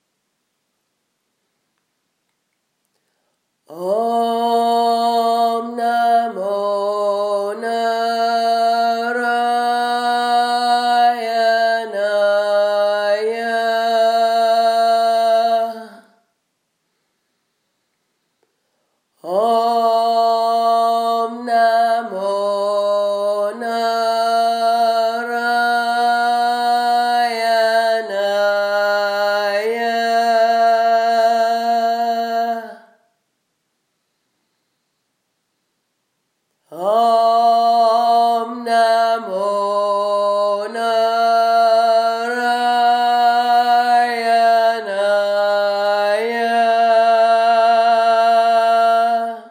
Whilst studying at an Ashram in Kerala, India, I was initiated into the Mantra of world peace and had the daily task of chanting…..
This is the opening call…